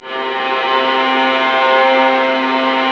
Index of /90_sSampleCDs/Roland L-CD702/VOL-1/STR_Vlas Bow FX/STR_Vas Sul Pont